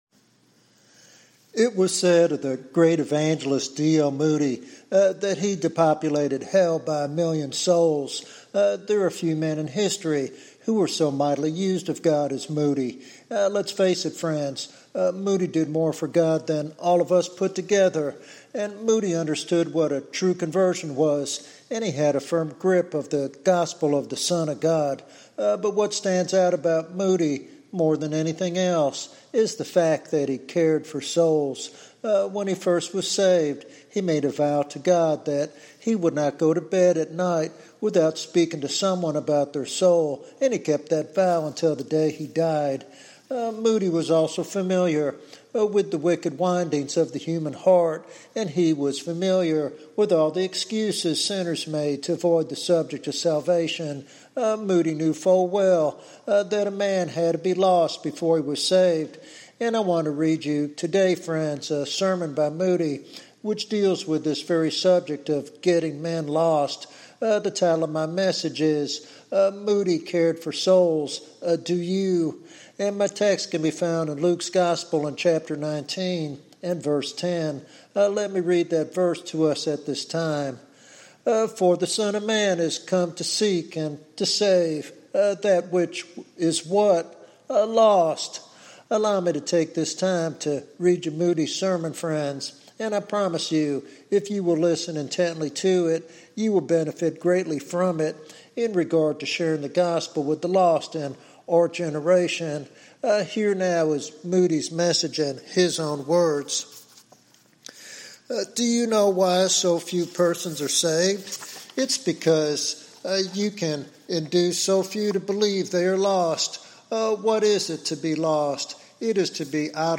Listeners are encouraged to follow Moody's example of persistent prayer, personal care, and follow-up to lead others to salvation. This sermon challenges believers to renew their commitment to soul-winning with the same passion Moody displayed.